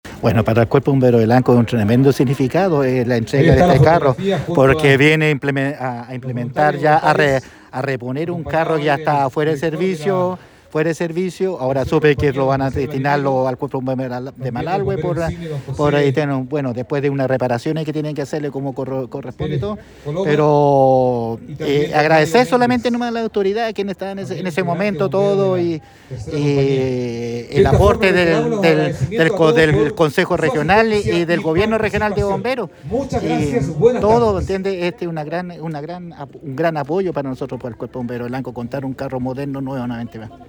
En una emotiva ceremonia, el Gobernador Regional de Los Ríos, Luis Cuvertino junto al Consejero Regional Elías Sabat entregaron un nuevo carro a la 3ra Compañía de Bomberos de Lanco, proyecto que tuvo una inversión de $171 millones, de los cuales $55 millones corresponden a un aporte de la Junta Nacional de Bomberos y $116 millones del GORE de Los Ríos, a través del Fondo Nacional de Desarrollo Regional (FNDR).